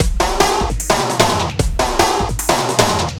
CRATE HF DRM 2.wav